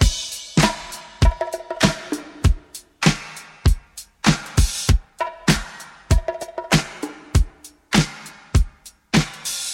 99 Bpm Drum Loop G# Key.wav
Free breakbeat sample - kick tuned to the G# note.
99-bpm-drum-loop-g-sharp-key-ZzU.ogg